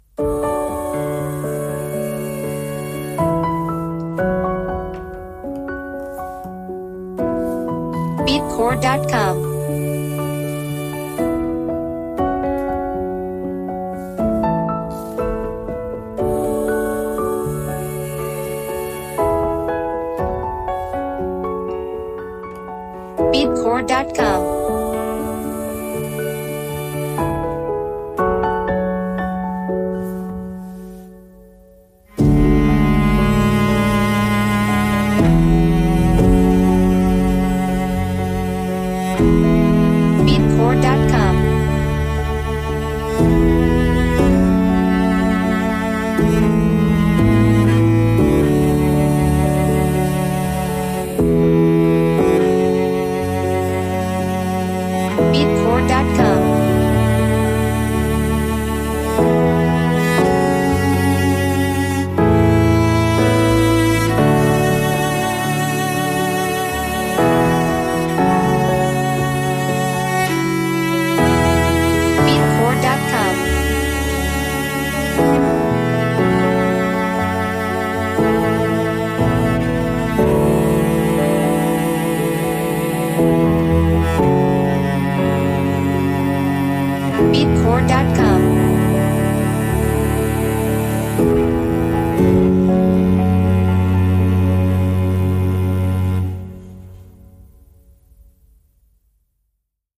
Instruments: Piano Strings Vocal